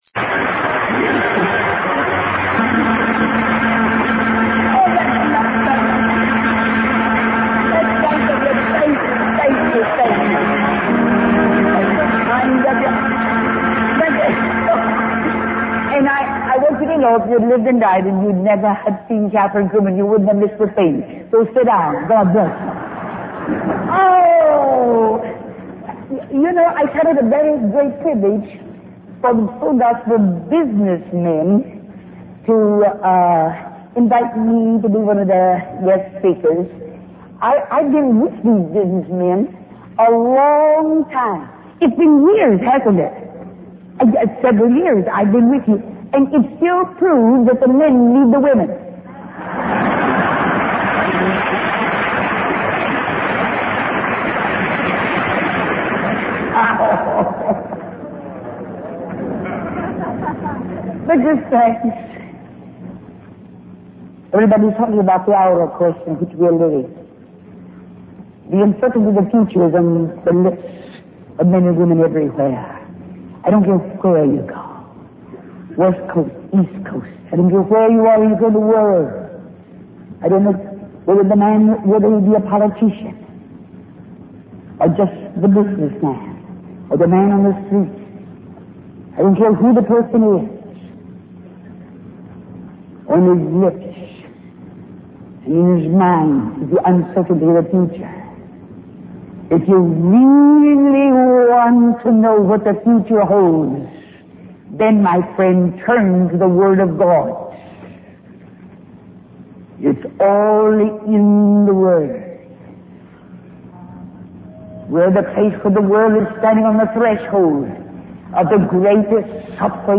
Kathryn Kuhlman's sermon highlights the indispensable role of the Holy Spirit in empowering believers amidst global uncertainties and tribulations.